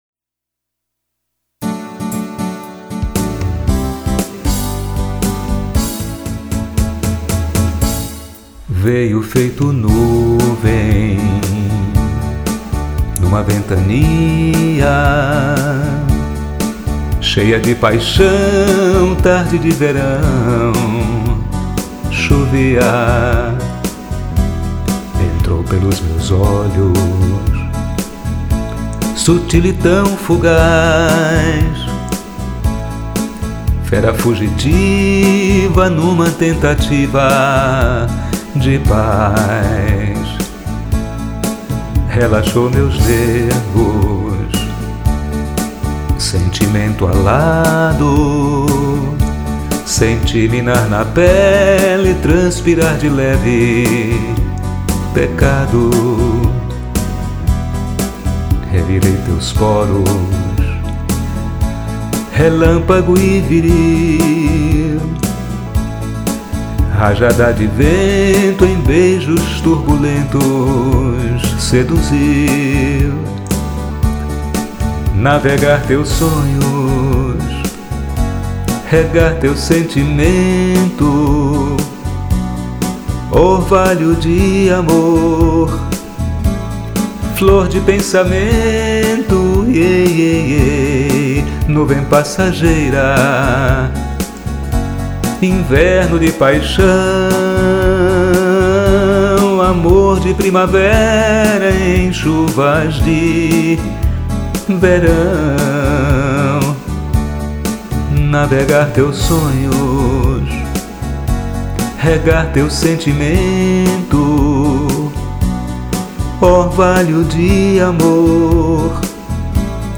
teclado